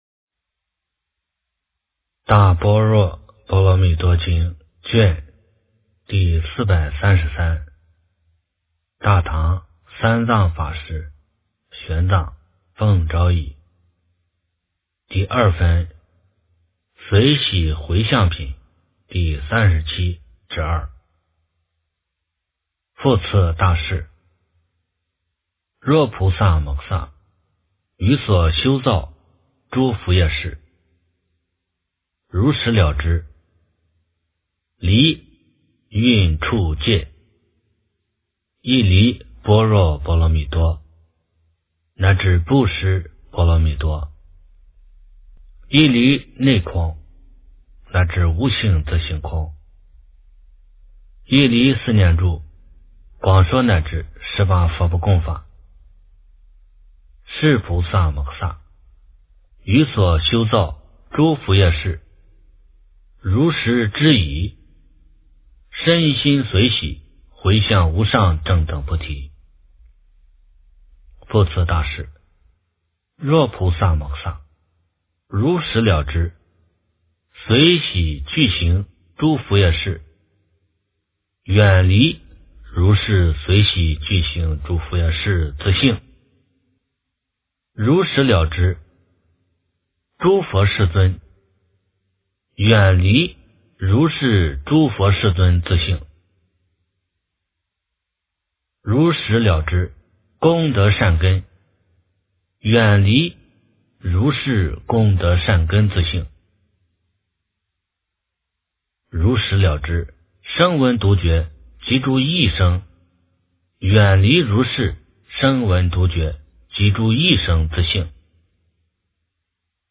大般若波罗蜜多经第433卷 - 诵经 - 云佛论坛